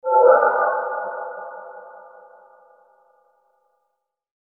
Royalty free sounds: Mysticism